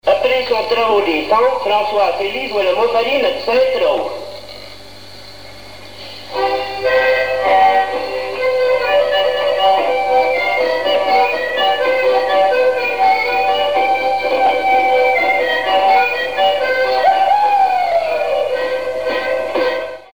danse : monfarine, montfarine, montferrine, montferine
circonstance : bal, dancerie
Pièce musicale inédite